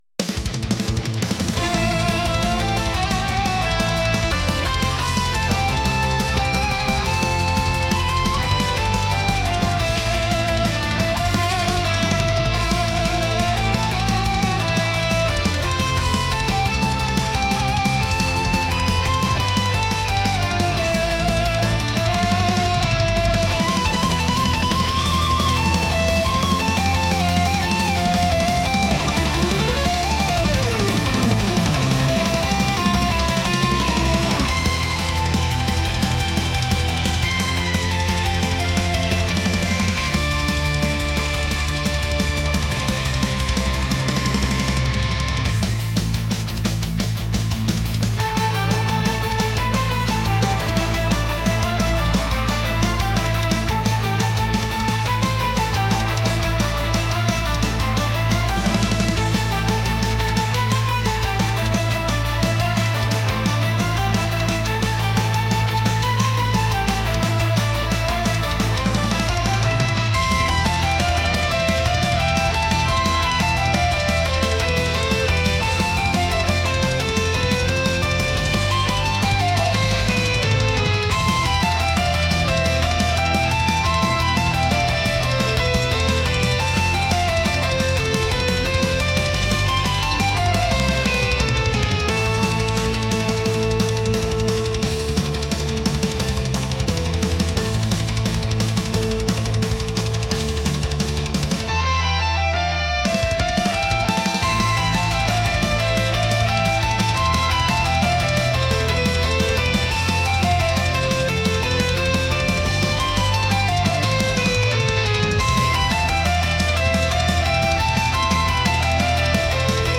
metal